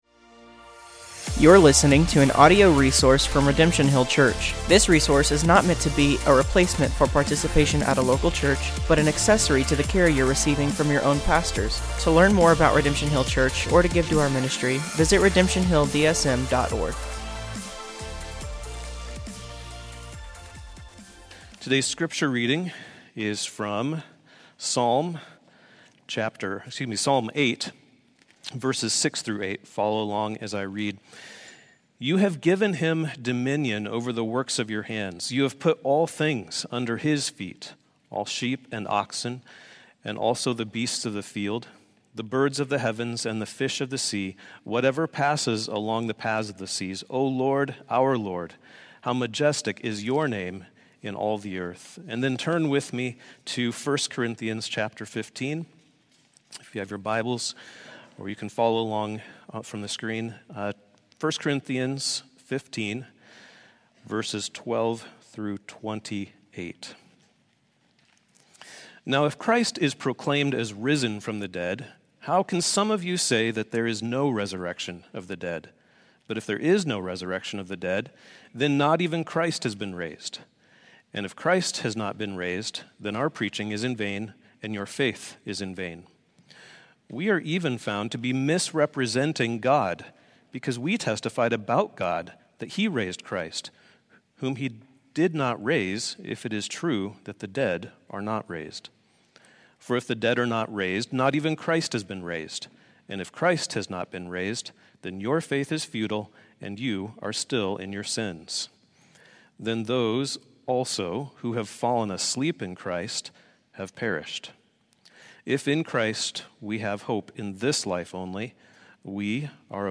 Sermons | Redemption Hill Church Des Moines, IA